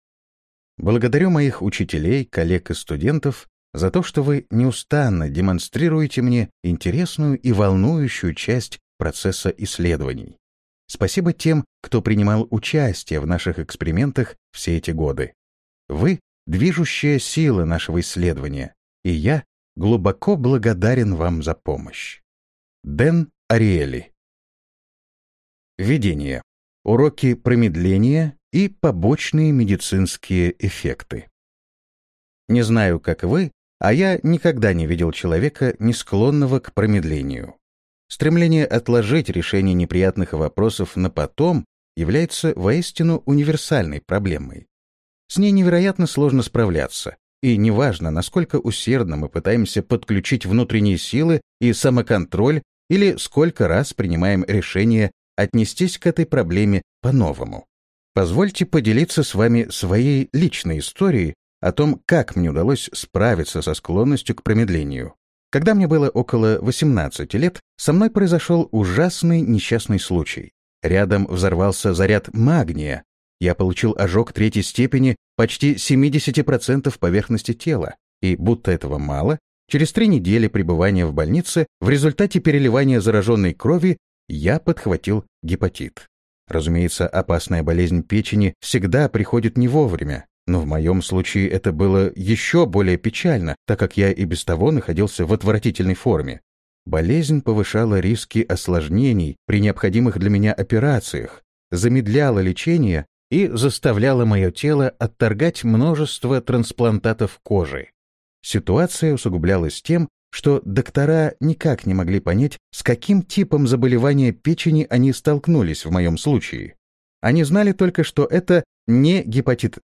Аудиокнига Позитивная иррациональность.